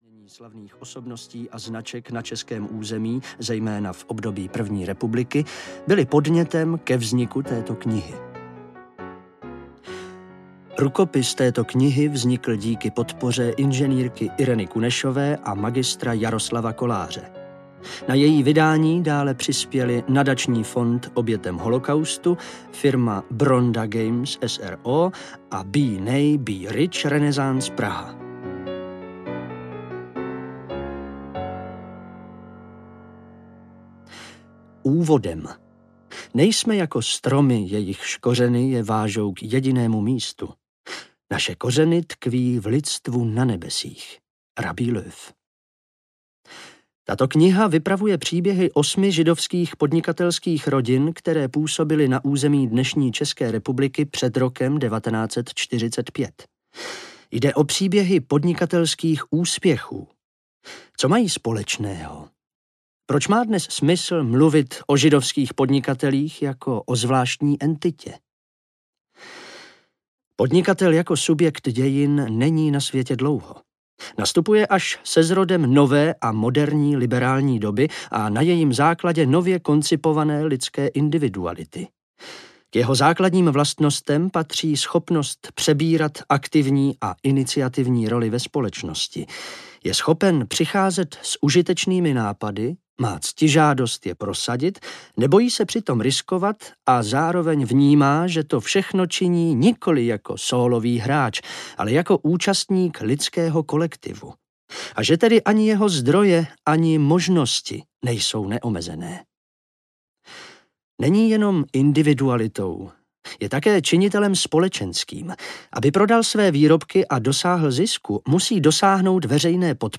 Podnikání pod Davidovou hvězdou audiokniha
Ukázka z knihy
podnikani-pod-davidovou-hvezdou-audiokniha